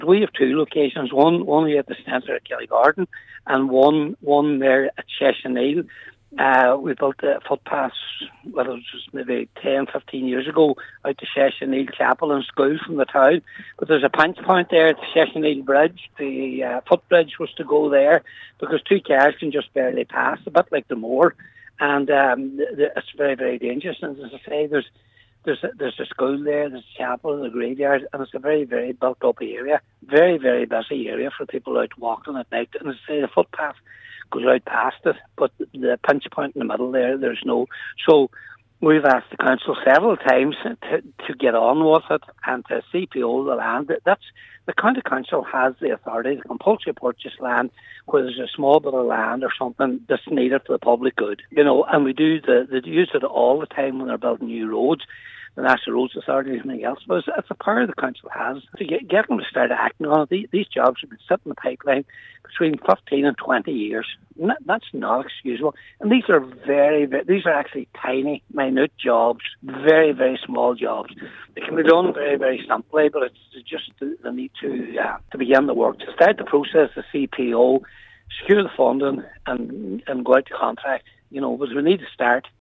The issue was raised by Patrick McGowan during a recent plenary meeting of the council.
Cllr McGowan said these projects have been delayed for far too long without any justifiable reason: